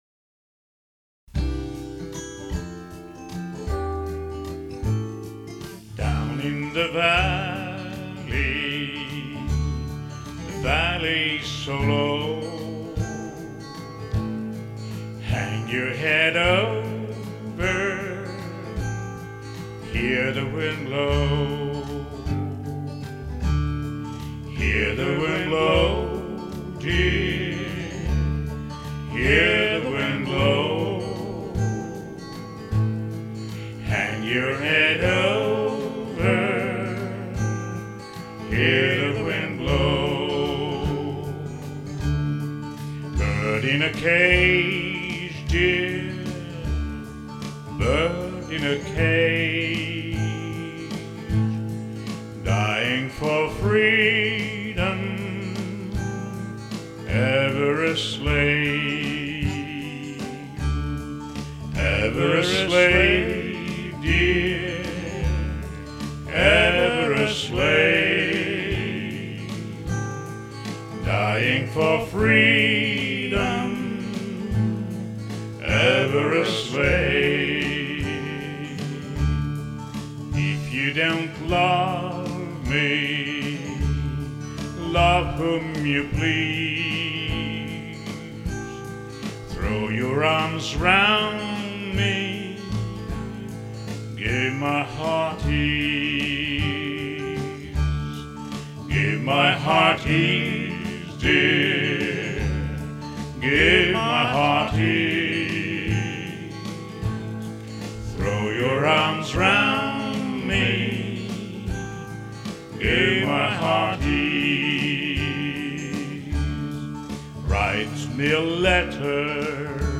one of our nation’s finest hillbilly songs